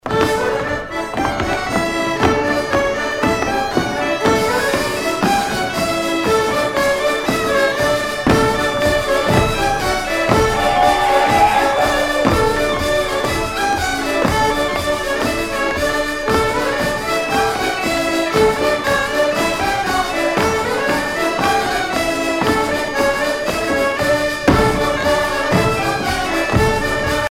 Chants brefs - A danser
danse-jeu : guimbarde
Pièce musicale éditée